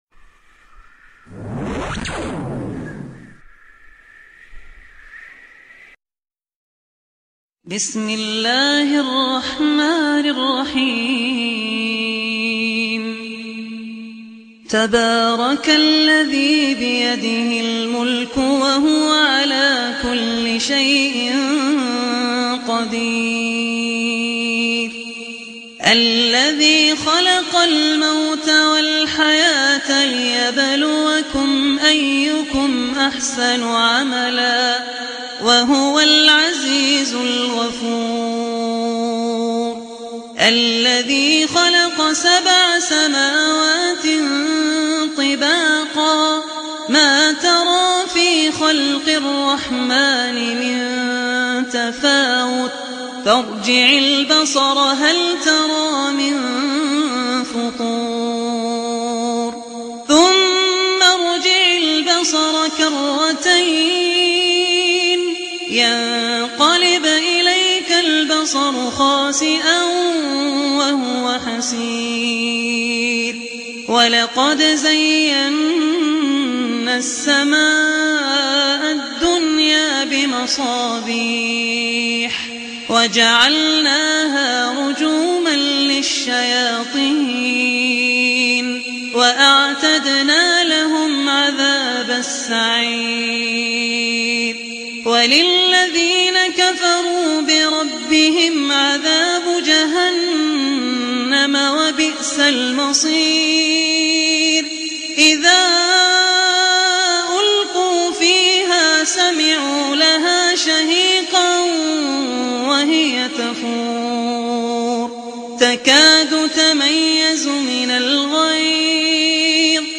Surah Mulk Recitation by Abdur Rahman Al Ossi